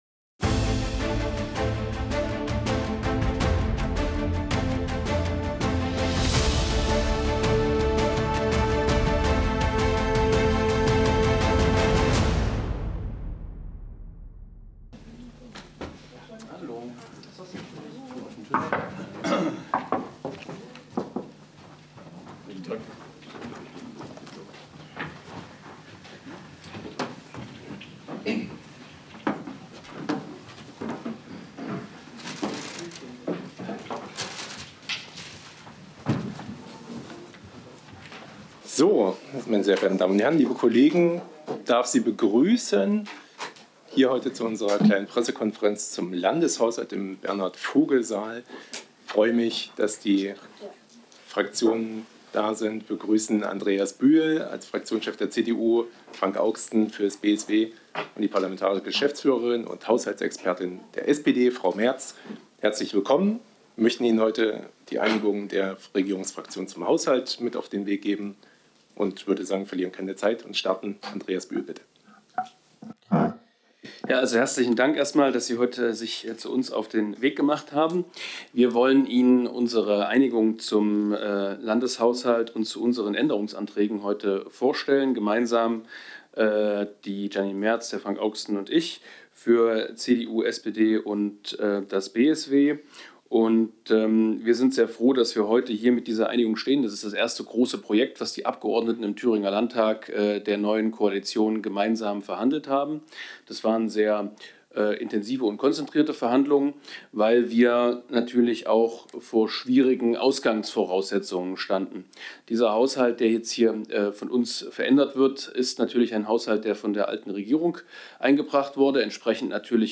Pressekonferenz zum Landeshaushalt - Bericht aus Erfurt
Die Fraktionen der Brombeer-Koalition wollen Gemeinden, St�dte und Landkreise in diesem Jahr mit insgesamt 155 Millionen Euro zus�tzlich unterst�tzen. Das haben die Vertreter der drei Regierungsfraktionen im Th�ringer Landtag gestern bei einer Pressekonferenz verk�ndet - gemeinsam mit anderen Neuigkeiten �ber den Th�ringer Landeshaushalt. Sehen Sie die Pressekonferenz im Original.